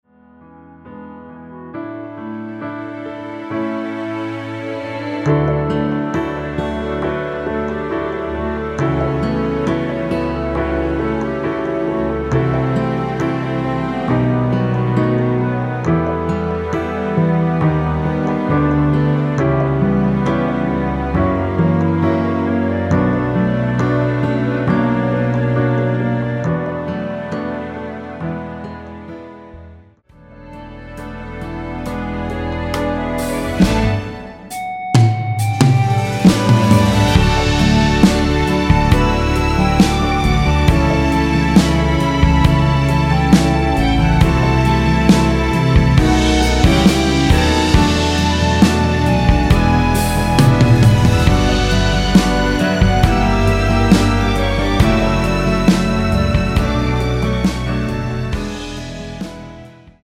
전주가 길어서 미리듣기는 중간 부분 30초씩 나눠서 올렸습니다.
원키에서(-2)내린 멜로디 포함된 MR입니다.
앞부분30초, 뒷부분30초씩 편집해서 올려 드리고 있습니다.
중간에 음이 끈어지고 다시 나오는 이유는